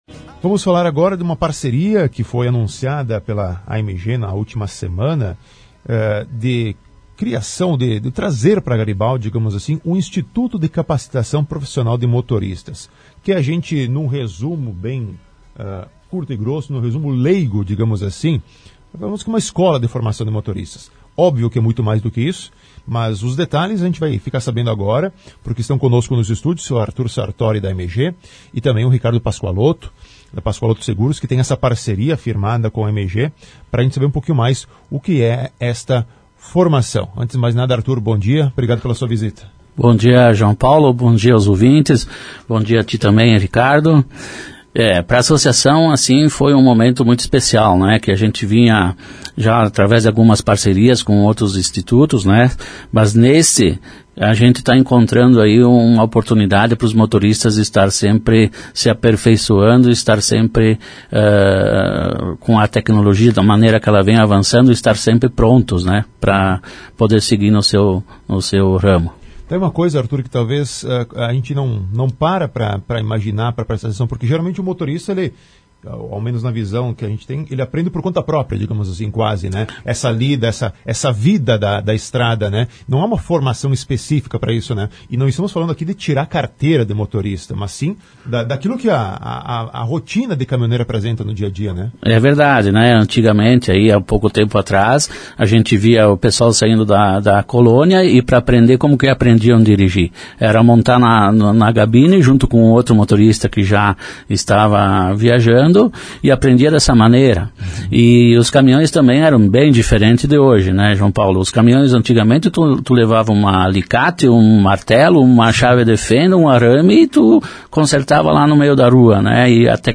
E nesta segunda-feira, 20/12, estiveram nos estúdios da Rádio Garibaldi